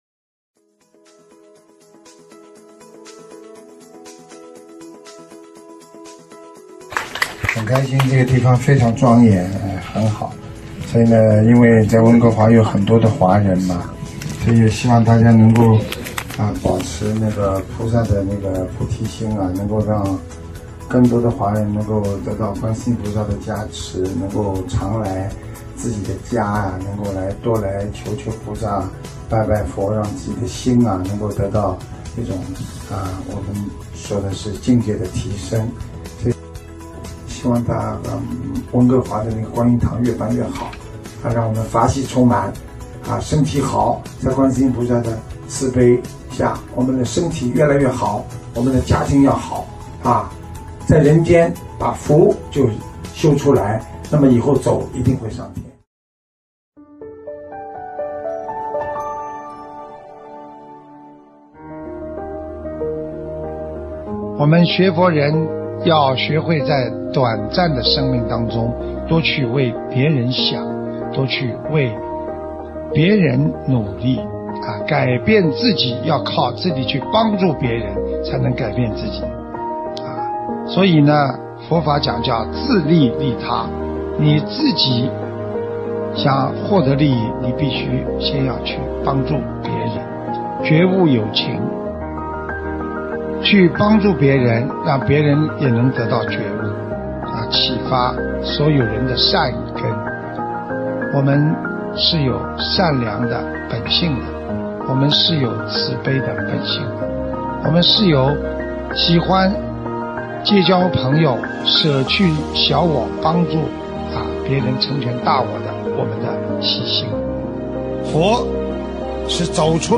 音频：温哥华观音堂新年花絮！2023年！